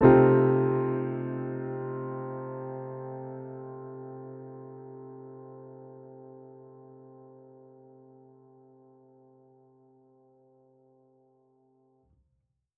Index of /musicradar/jazz-keys-samples/Chord Hits/Acoustic Piano 1
JK_AcPiano1_Chord-Cm13.wav